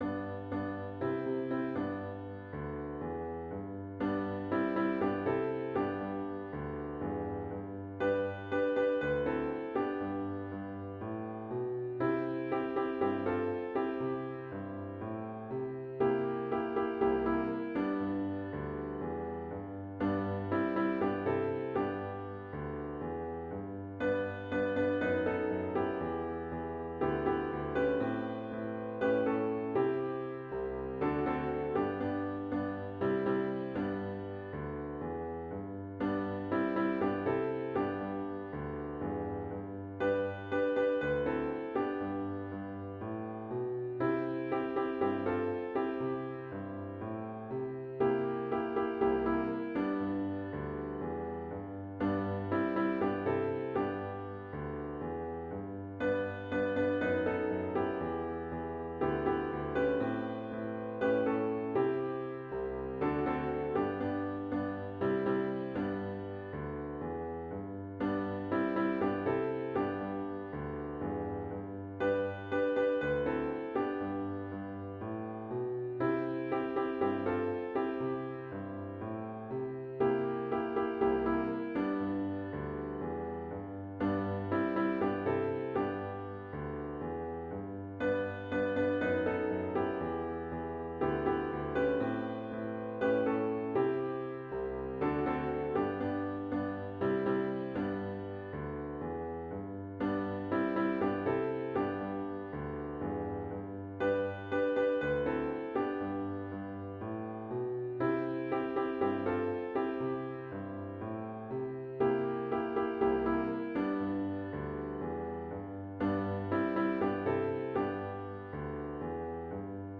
*CLOSING HYMN “This Little Light of Mine”
zz-v27-This-Little-Light-of-Mine-4vs-piano-with-intro-MP3.mp3